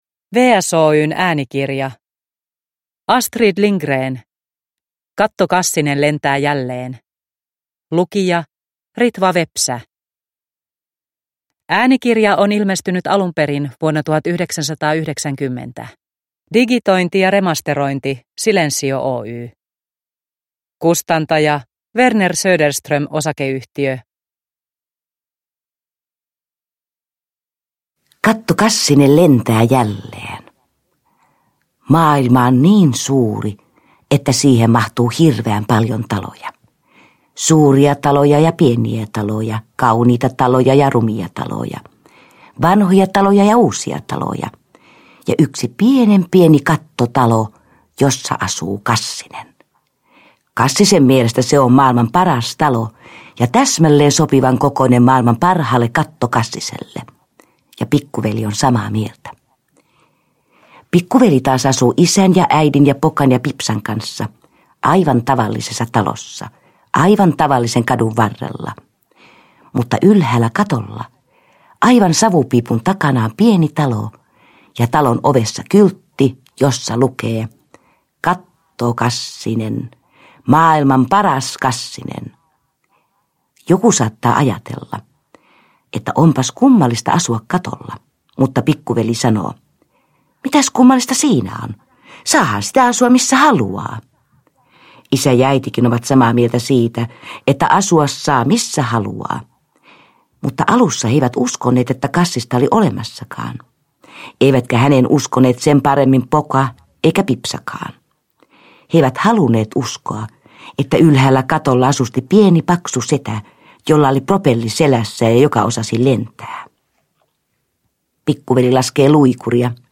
Katto-Kassinen lentää jälleen – Ljudbok – Laddas ner